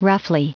Prononciation du mot roughly en anglais (fichier audio)
Prononciation du mot : roughly